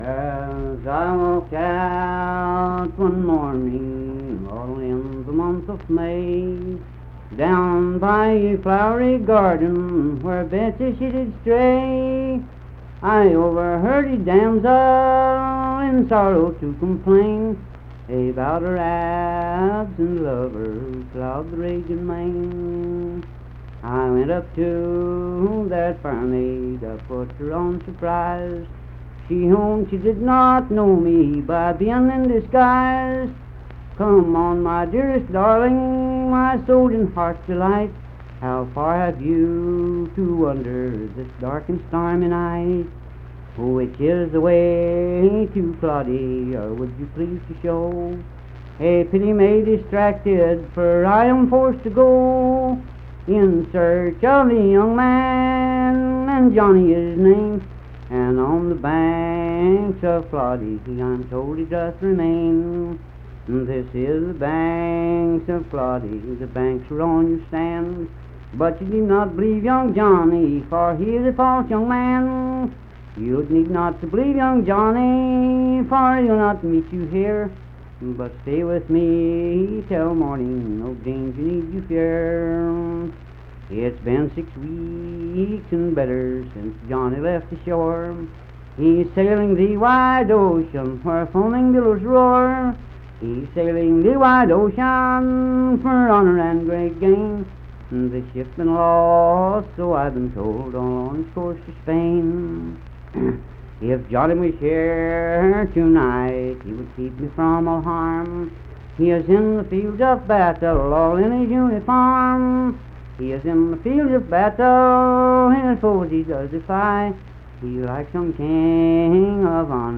Unaccompanied vocal
Verse-refrain 7(8).
Voice (sung)
Mingo County (W. Va.), Kirk (W. Va.)